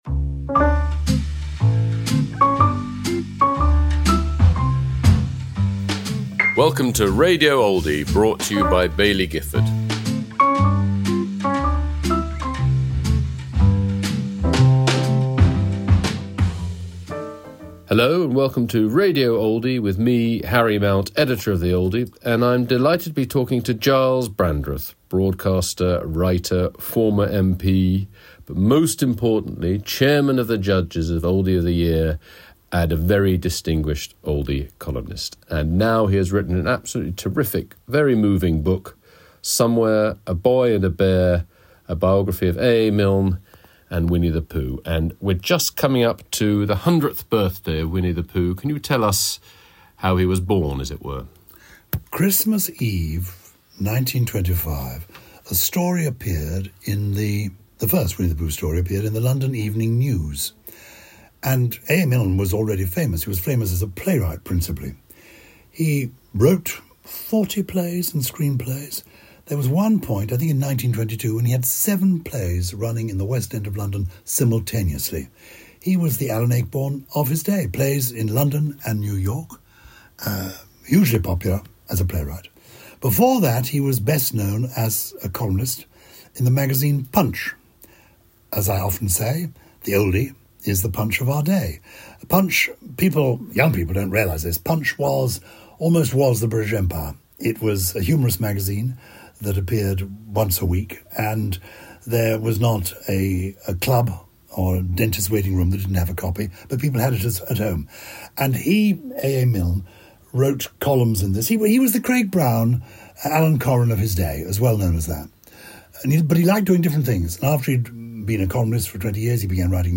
Gyles Brandreth in conversation with Harry Mount